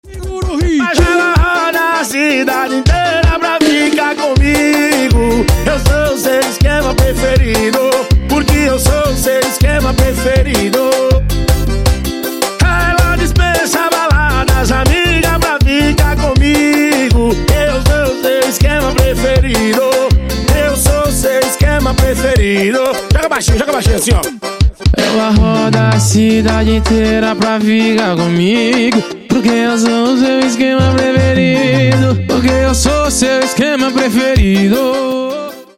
Латинские Рингтоны
Танцевальные Рингтоны